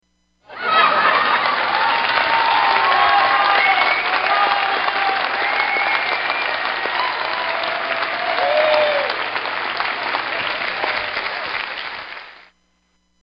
applause with laughs
Category: Sound FX   Right: Personal